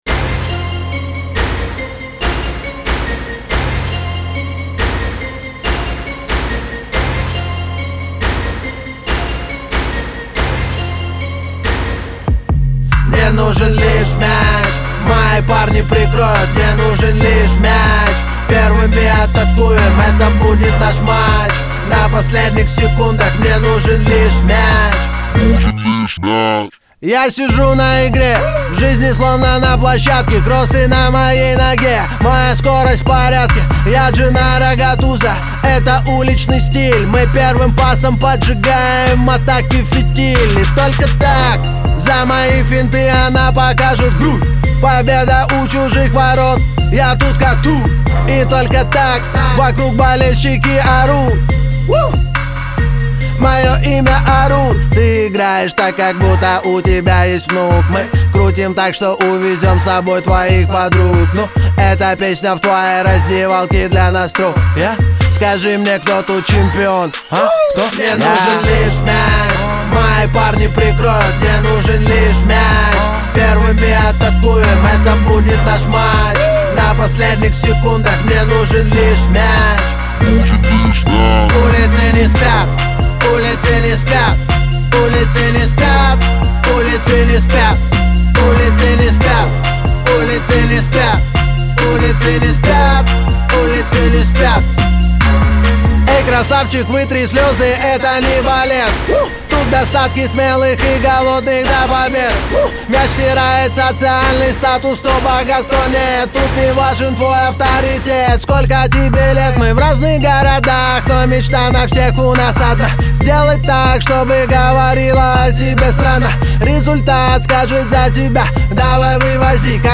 Главная » Файлы » Русский рэп 2016